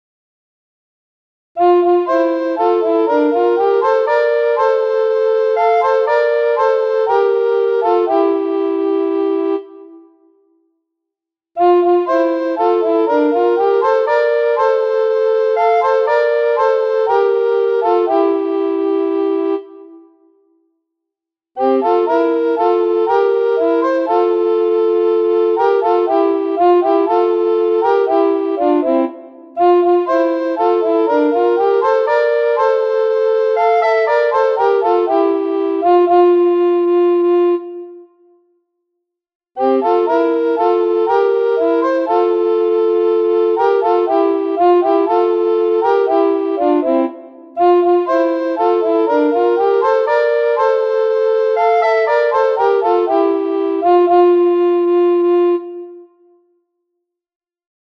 Recorder Duets Music Files